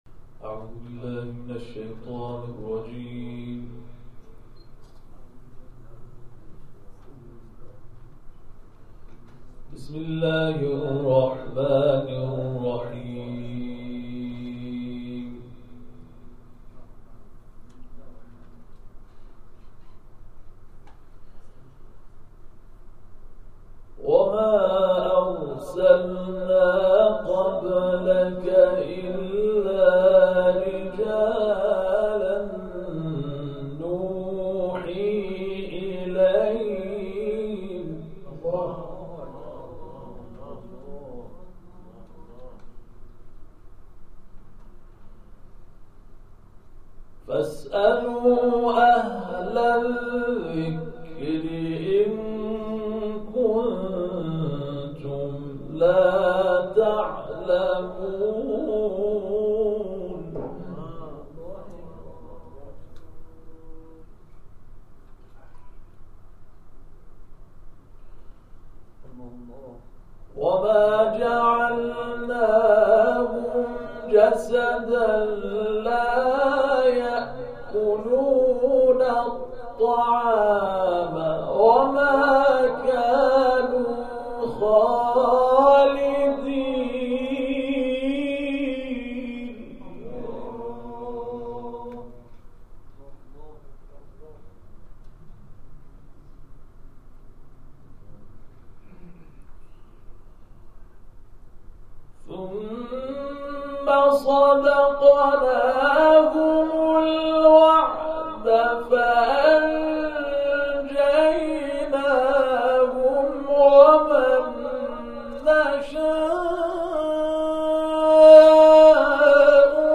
در پایان تلاوت های منتخب این جلسه قرآن ارائه می‌شود.